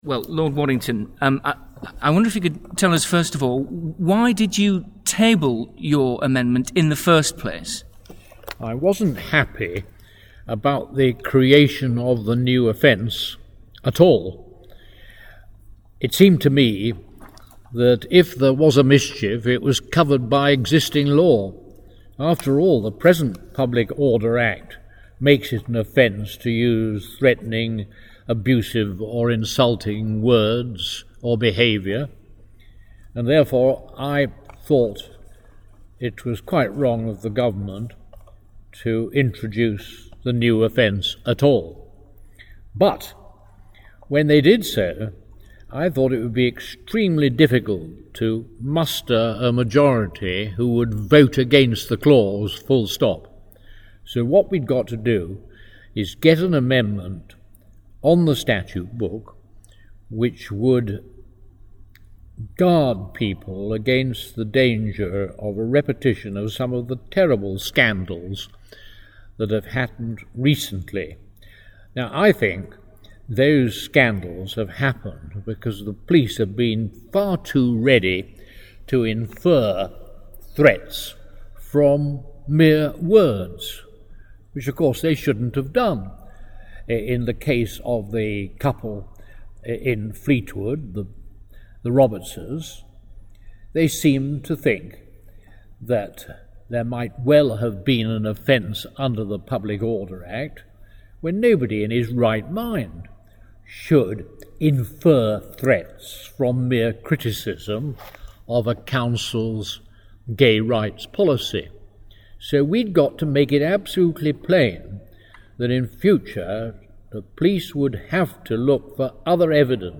Click to listen to an interview with Lord Waddington